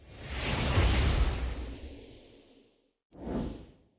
ALERT_AUDIO.wav